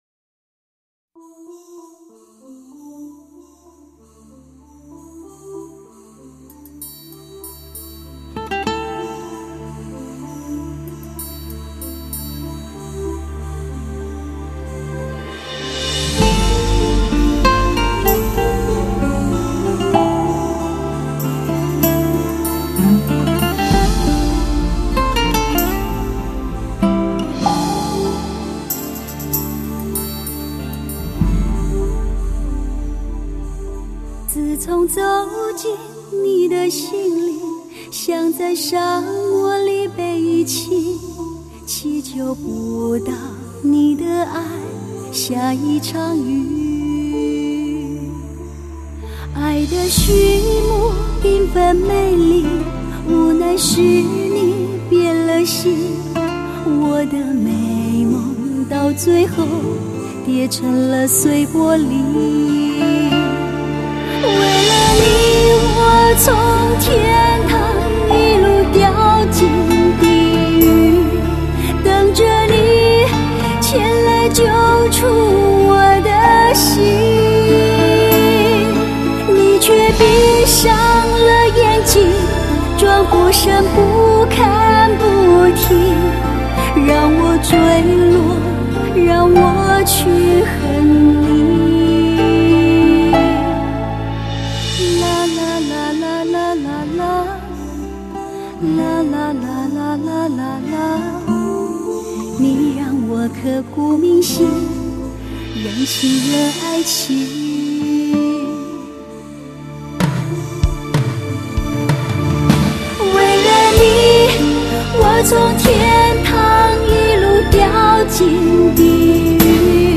精彩绝伦12首美式新听觉情歌